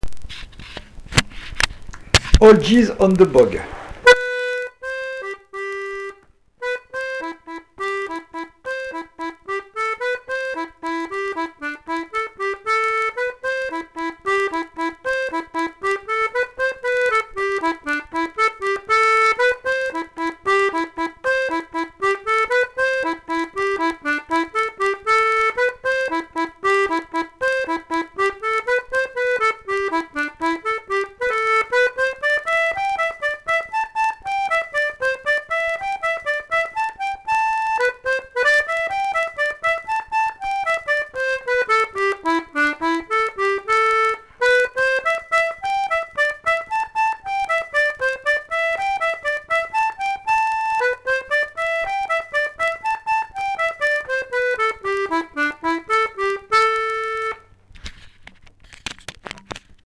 l'atelier d'accordéon diatonique